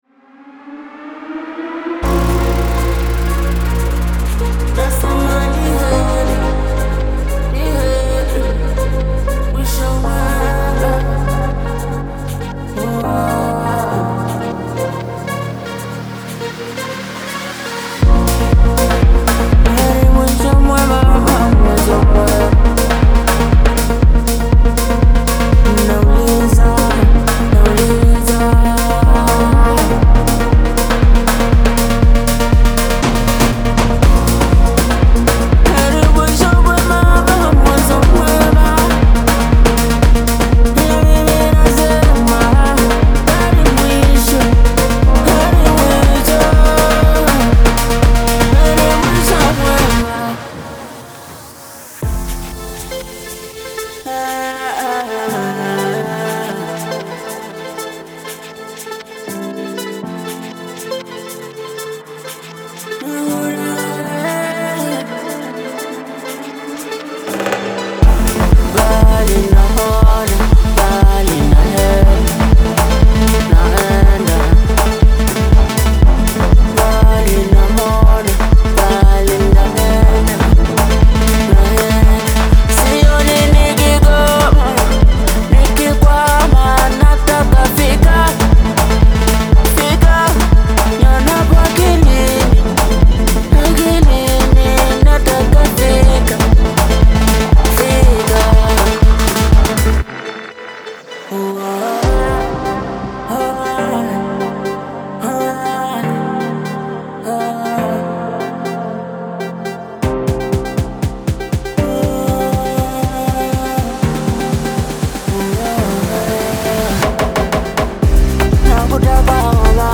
Genre:Afro House
南アフリカで生まれたこのスタイルは、モダンなメロディックハウスとメロディックテクノにアフロサウンドを融合させたものです。
30 Afro Percussion Loops
20 Atmos Synth Loops
20 Vocal Chants